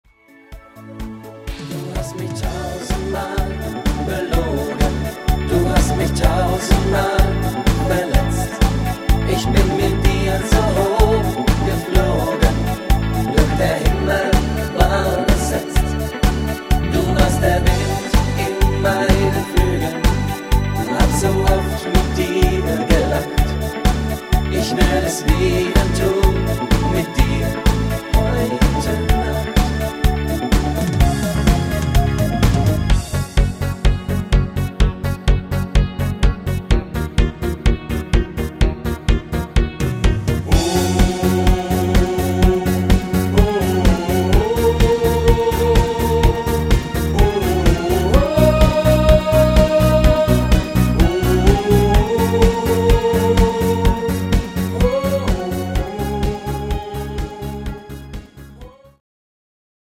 Männerversion